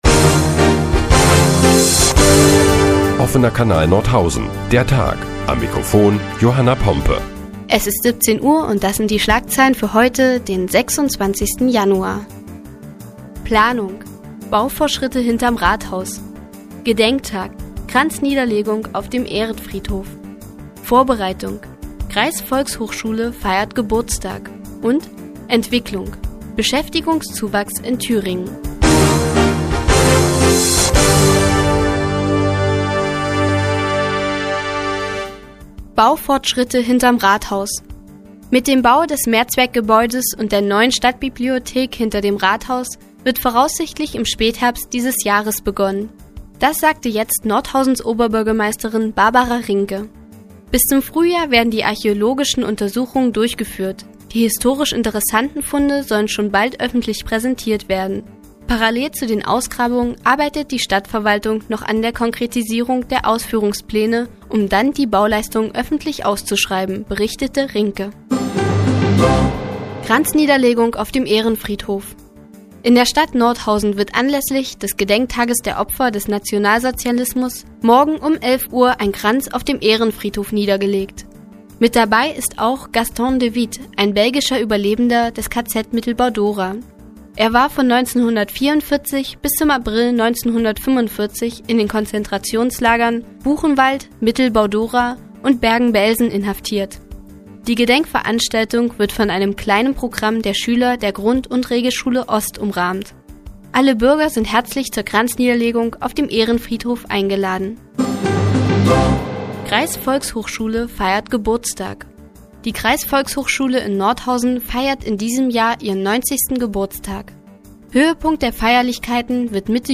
Die tägliche Nachrichtensendung des OKN ist nun auch in der nnz zu hören. Heute geht es unter anderem um Baufortschritte hinterm Rathaus und um den 90. Geburtstag der Kreisvolkshochschule.